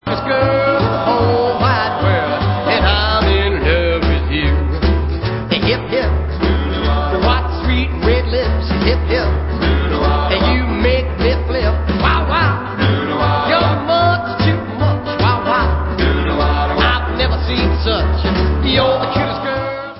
50's rock